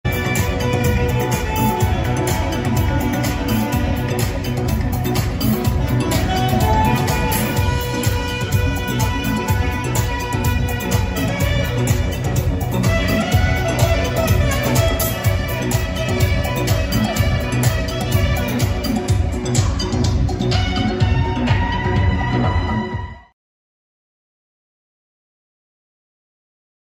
Open format, can play any genres and style.